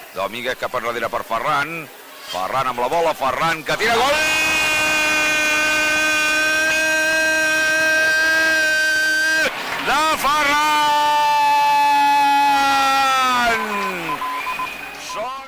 Narració d'un partit d'hoquei patins: gol d'un jugador del Reus Deportiu
Esportiu